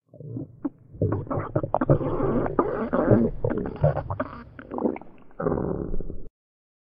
Compresses and normalizes vore sounds 2021-07-18 06:21:01 +00:00 61 KiB Raw History
digest_06.ogg